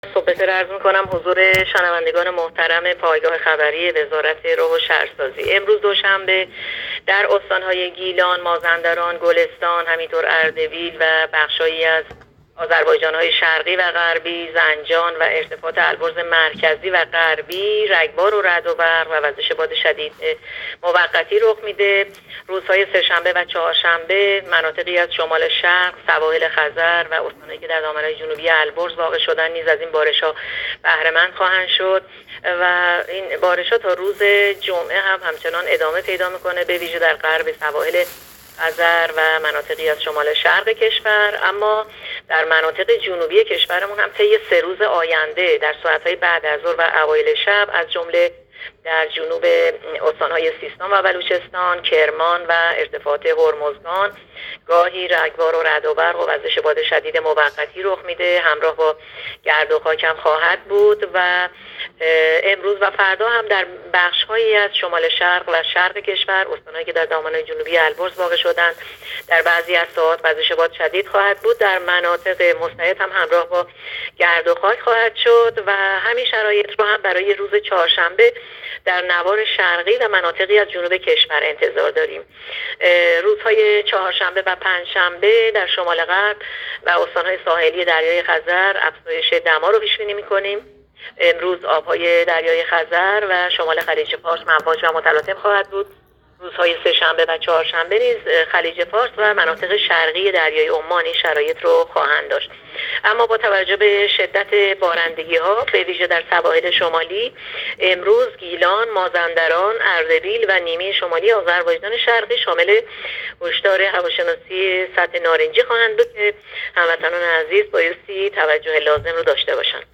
گزارش رادیو اینترنتی پایگاه‌ خبری از آخرین وضعیت آب‌وهوای ۳۱ شهریور؛